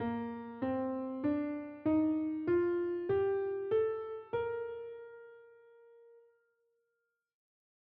Bb-Major-Scale-S1.wav